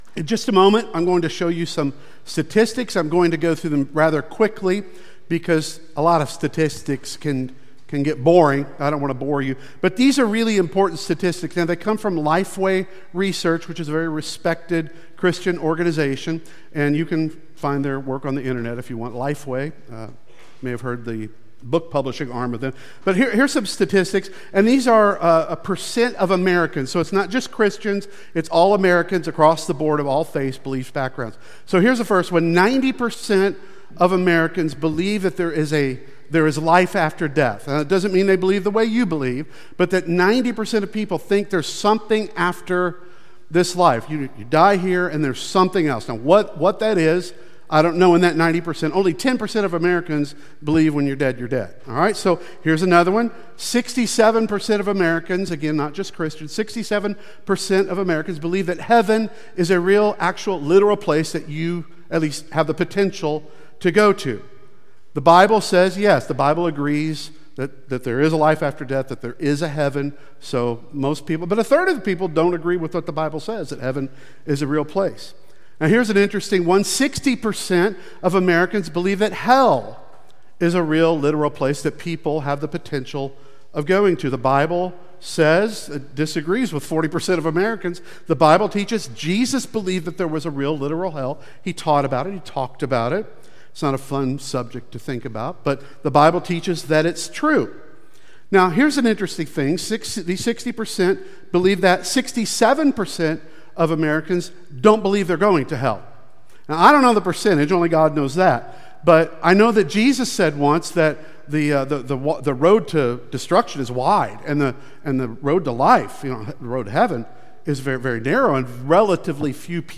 The Questions of Jesus Service Type: Sunday Worship Service Speaker